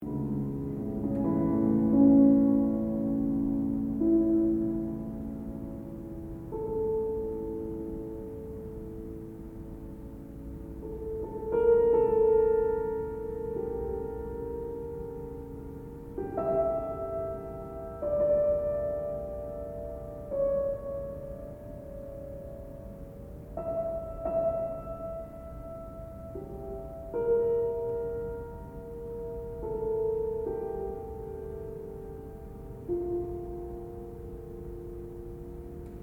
But now! Beethoven instructs the pianist to keep the pedal down, and…here comes the recitative:
recitativtempest2.mp3